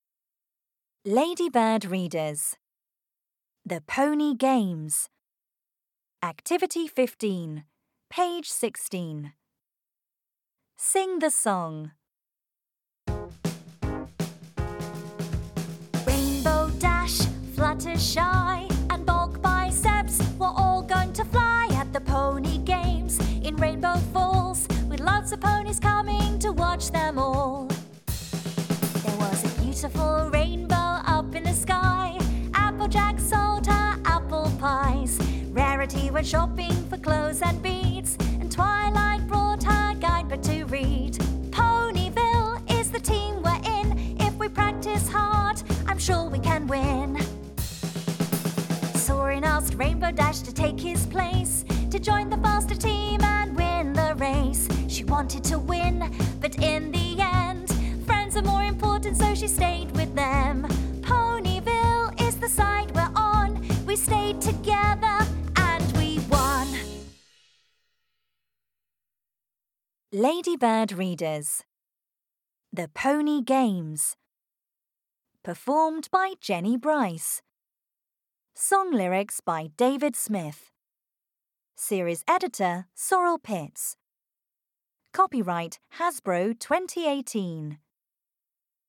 Audio UK
Song